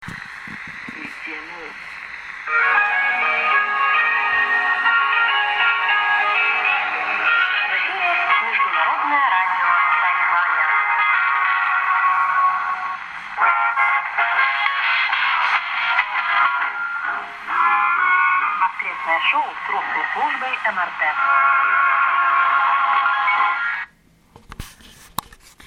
Russian Station ID